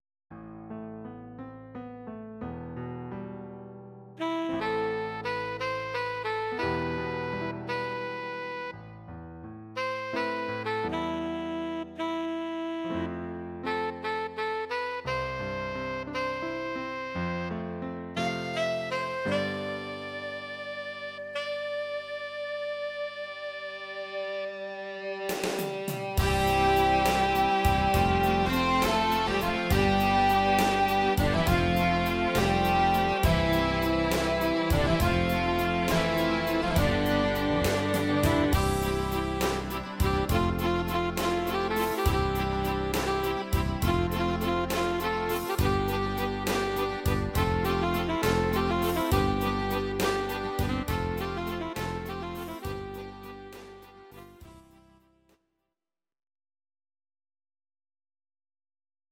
Audio Recordings based on Midi-files